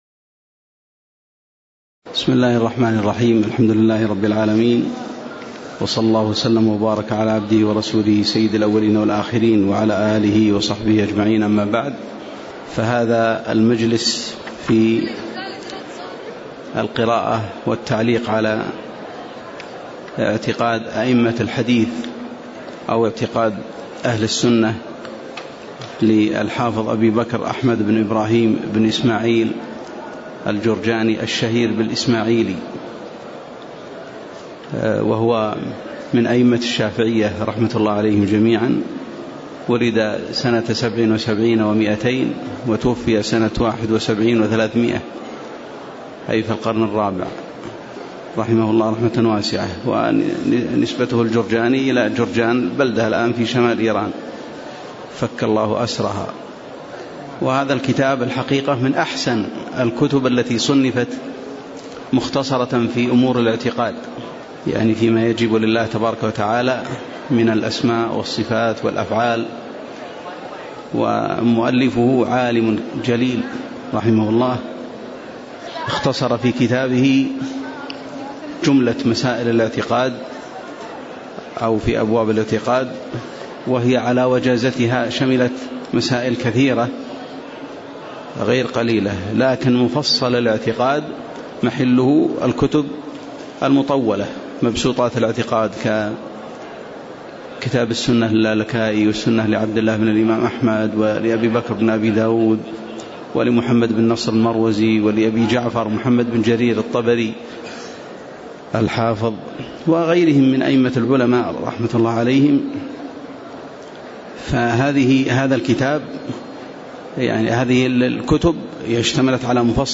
تاريخ النشر ١٦ جمادى الآخرة ١٤٣٨ هـ المكان: المسجد النبوي الشيخ